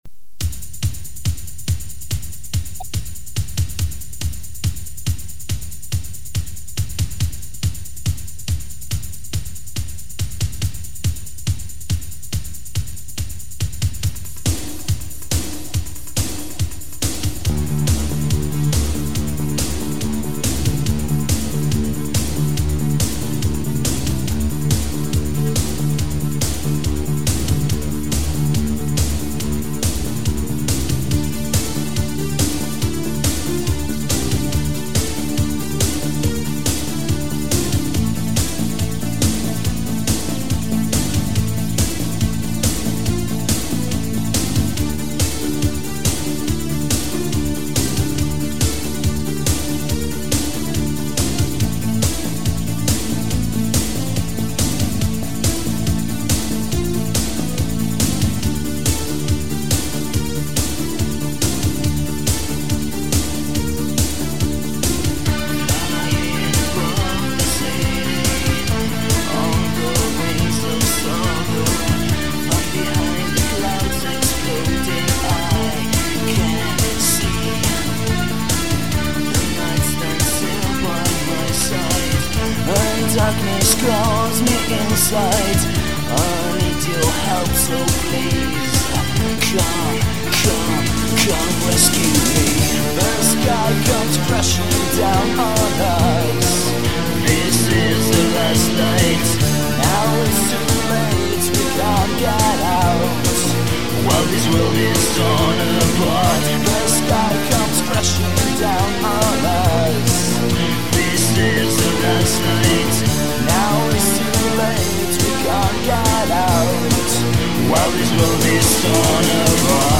Vocals
Guitar
Bass and programming